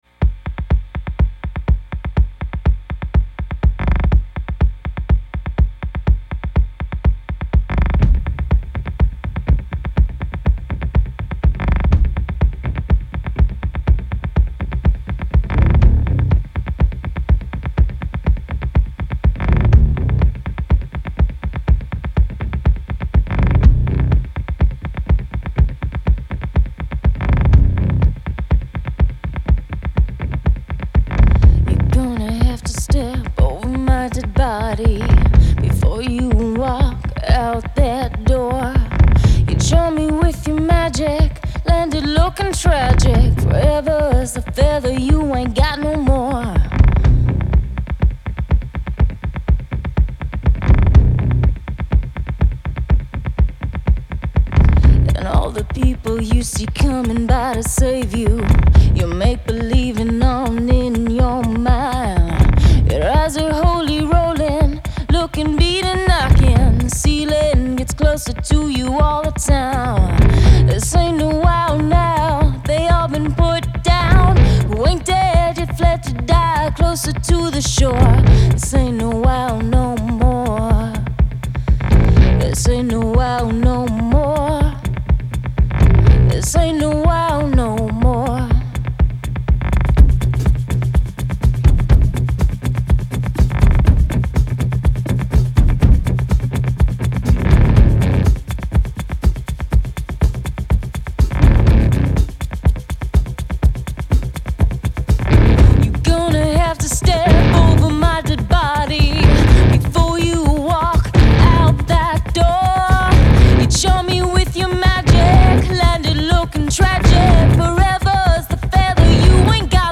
Genre : Pop, Rock, Alternatif et Indé